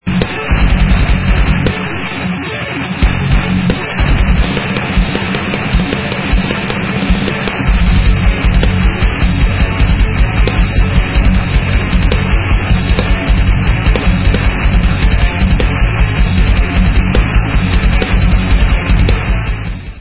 sledovat novinky v oddělení Rock/Alternative Metal